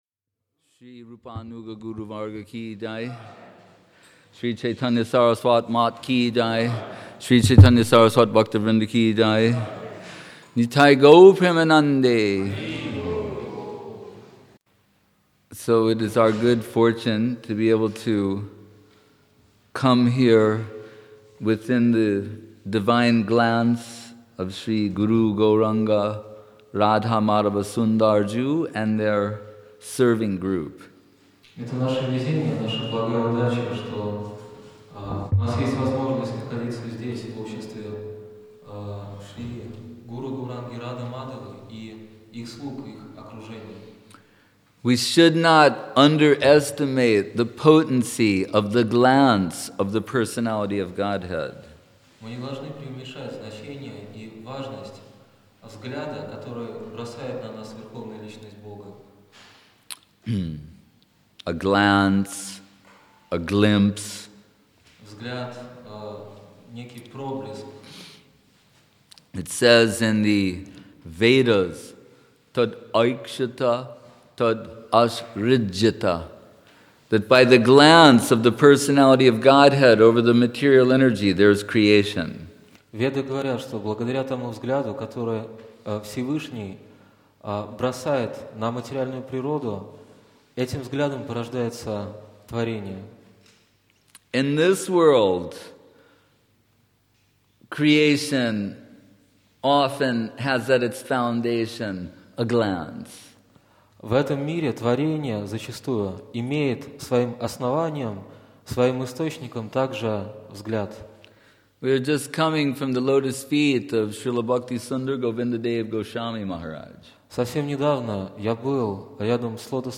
Place: Sri Chaitanya Saraswat Math Saint-Petersburg